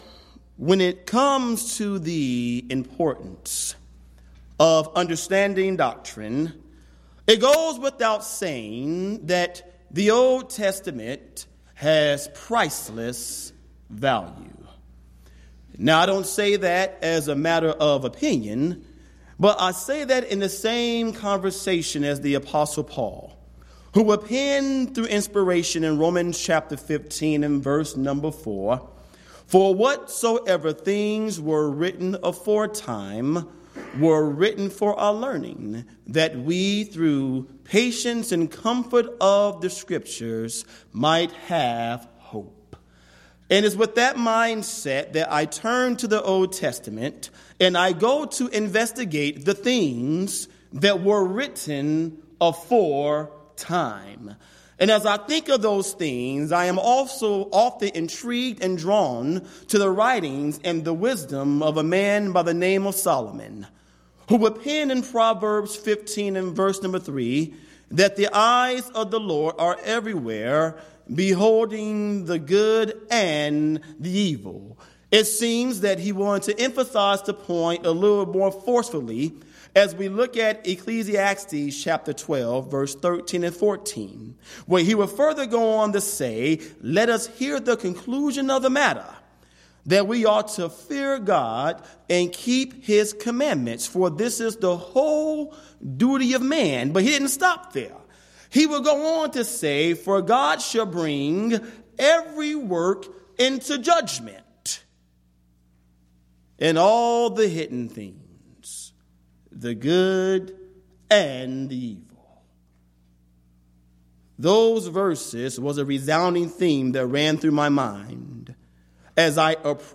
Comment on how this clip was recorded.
Event: 10th Annual Schertz Lectures Theme/Title: Studies in Ezekiel